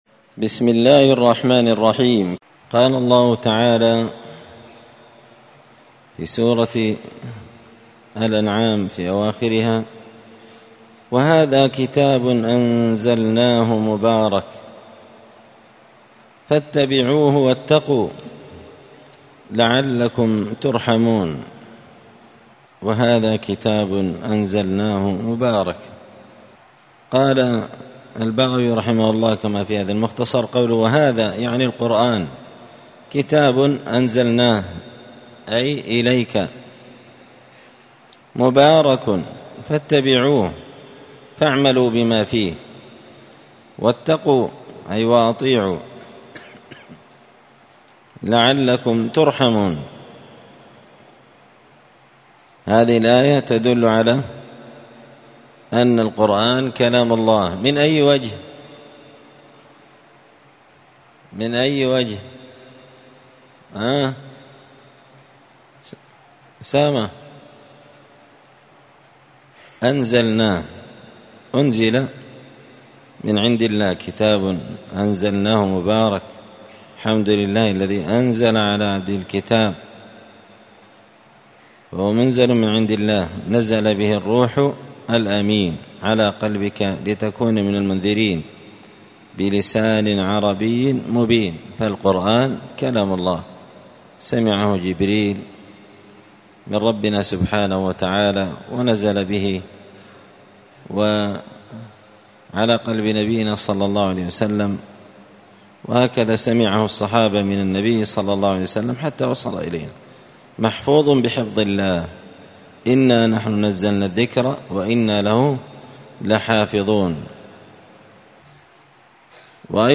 📌الدروس اليومية